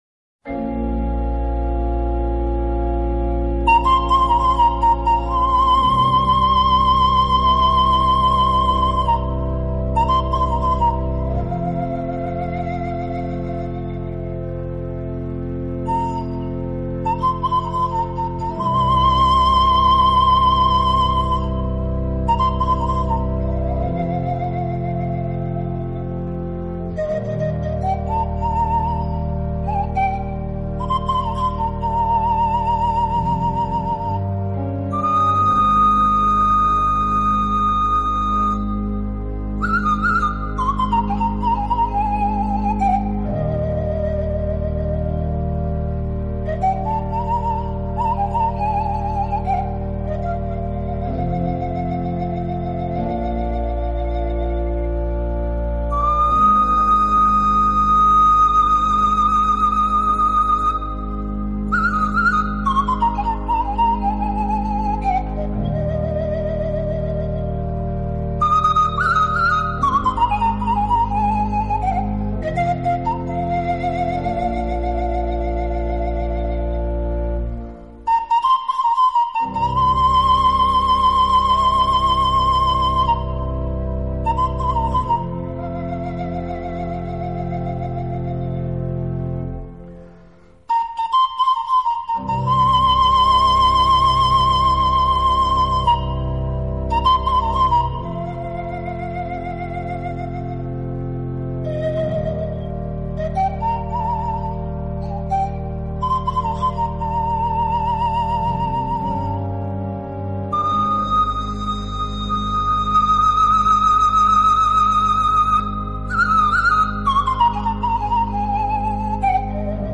置身于世外桃园，尽情享受这天簌之音……